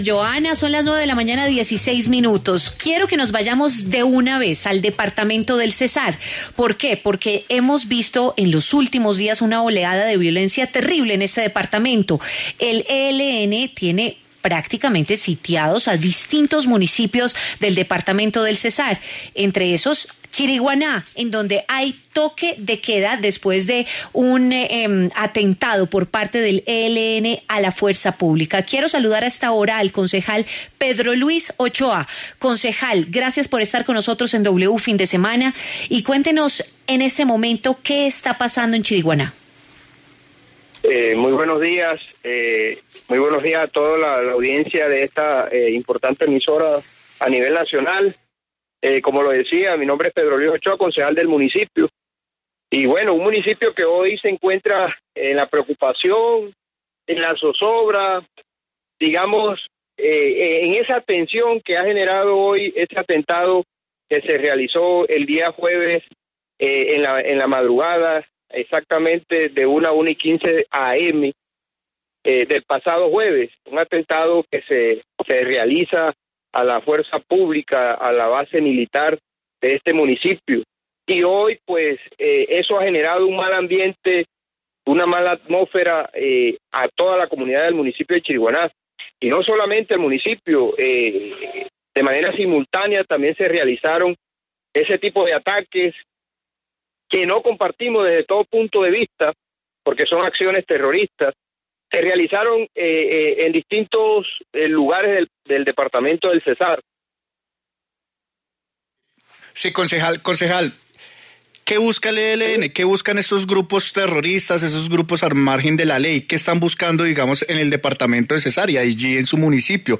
Pedro Luis Ochoa, concejal de Chiriguaná, Cesar, habló en W Fin de Semana sobre la situación del municipio, asediado por grupos armados.